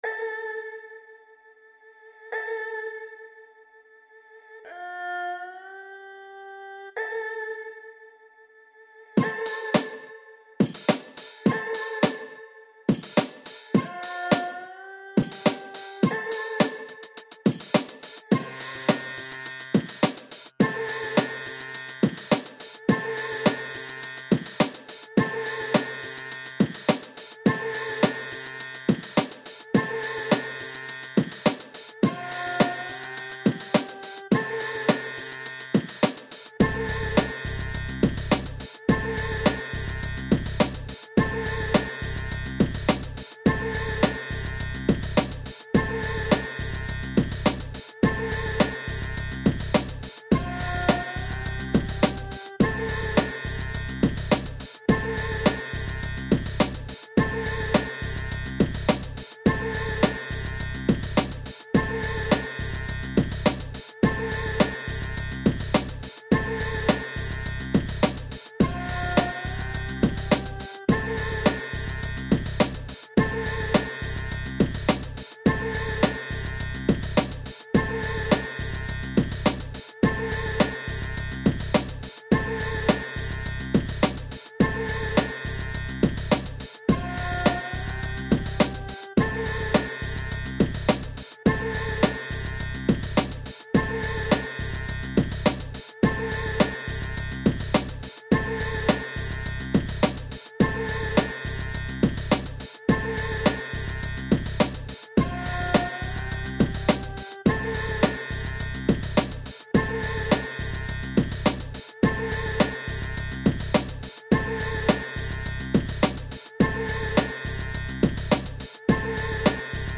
instumental 中華ＶＳＴi大活躍。